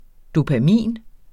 Udtale [ dopaˈmiˀn ]